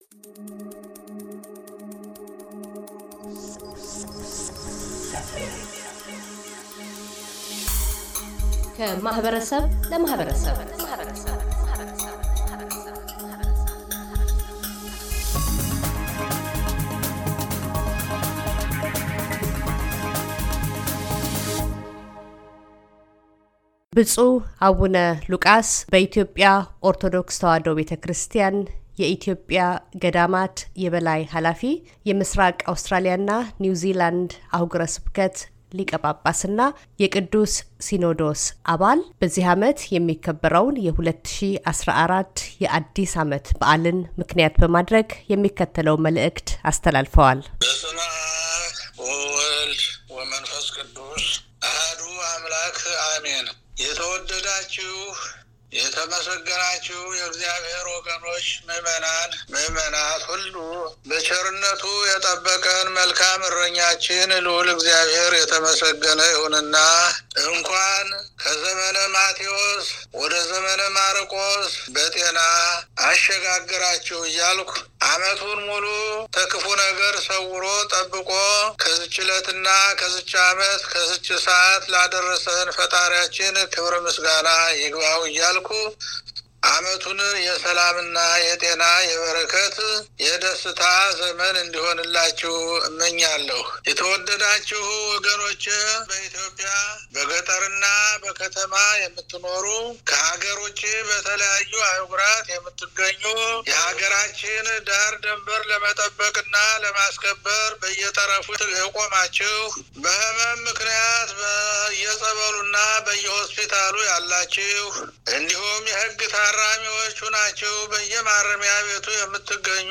ብፁዕ አቡነ ሉቃስ - በኢትዮጵያ ኦርቶዶክስ ቤተክርስቲያን የኢትዮጵያ ገዳማት ኃላፊ፣ የምሥራቅ አውስትራሊያና ኒውዝላንድ አኅጉረ ስብከት ሊቀ ጳጳስና የቅዱስ ሲኖዶስ አባል፤ የአዲስ ዓመት መልዕክት።